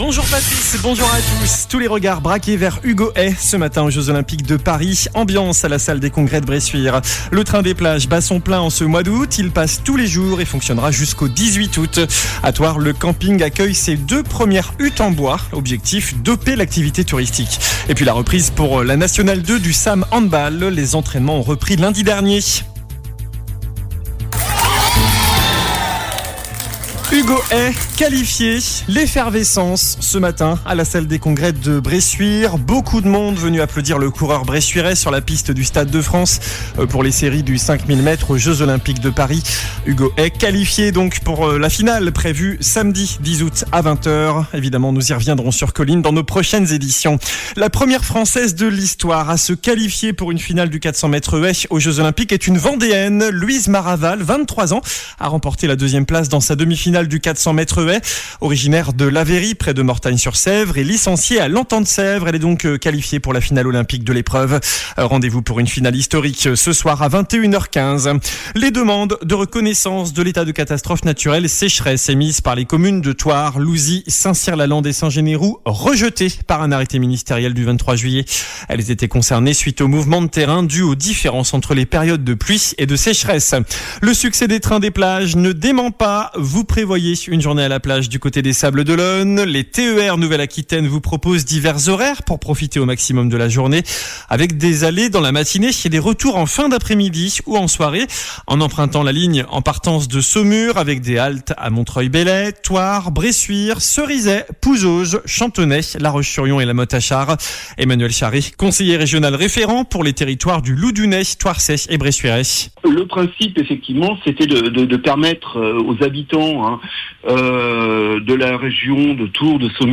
JOURNAL DU MERCREDI 07 AOÛT ( MIDI )